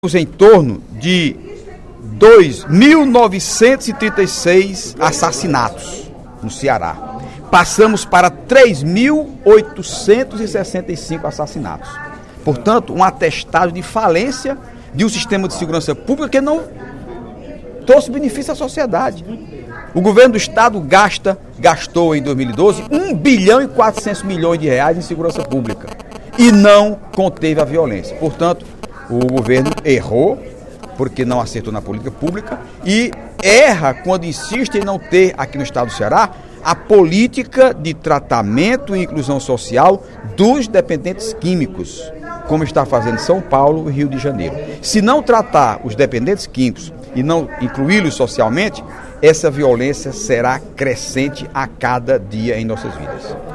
O deputado Heitor Férrer (PDT) fez pronunciamento nesta quarta-feira (06/02), no primeiro expediente, para afirmar que o Governo do Estado tem mais virtudes que defeitos.